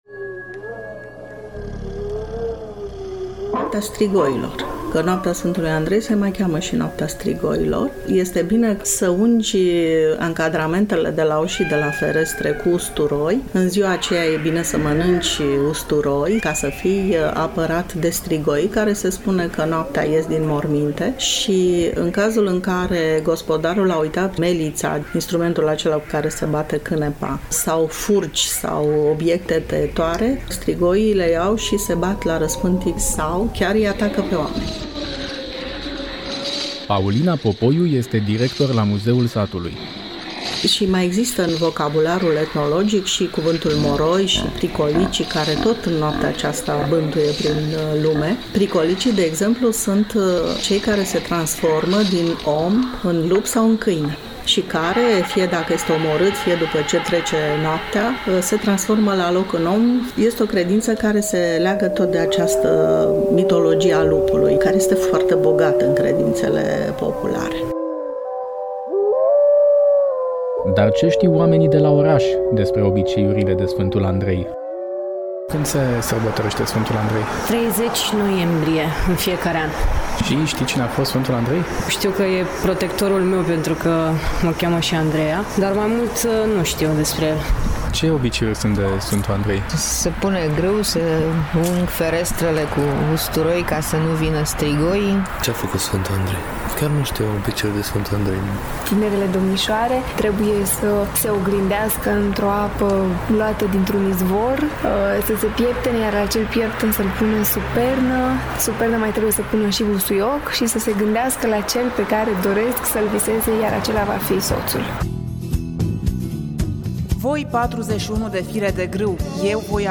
Reportaj